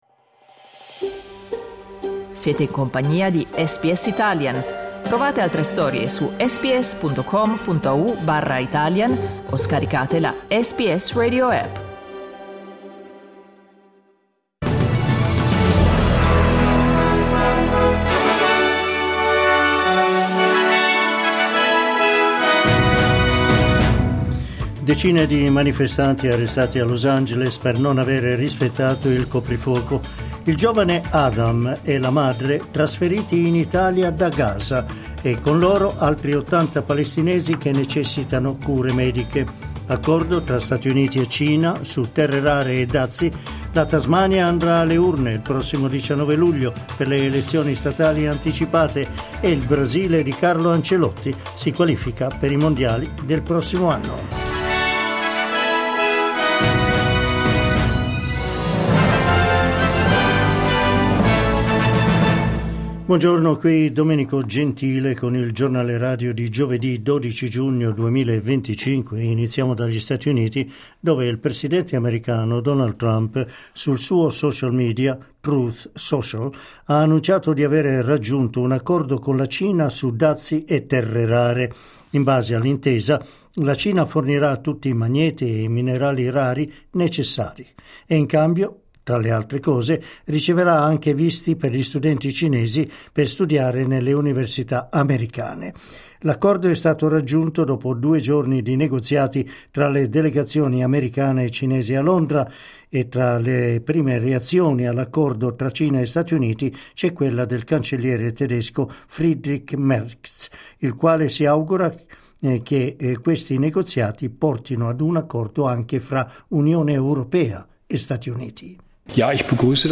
Giornale radio giovedì 12 giugno 2025